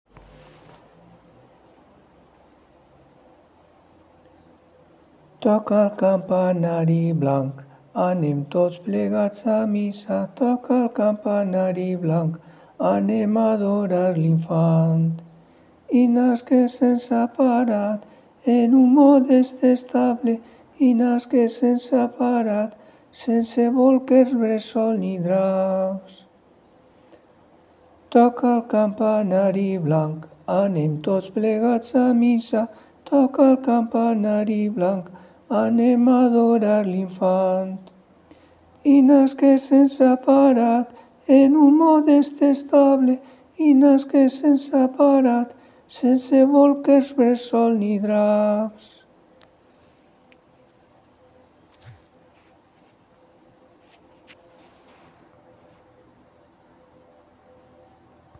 Cançons de Nadal tradicionals de la terra
A continuació, posem un enregistrament de com la cantí (la recordava prou) el 21 de desembre del 2024, d’acord amb com em digué ma mare que sona.